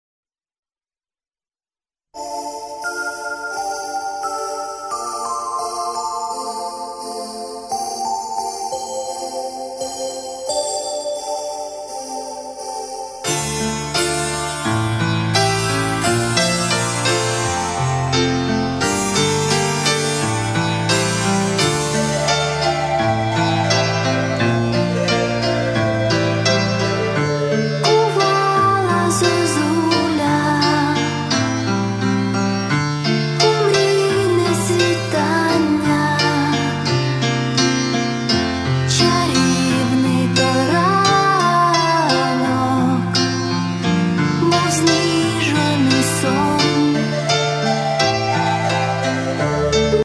Початок » CDs» Поп музика Мій аккаунт  |  Кошик  |  Замовити